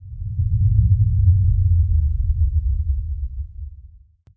PixelPerfectionCE/assets/minecraft/sounds/ambient/cave/cave4.ogg at mc116
cave4.ogg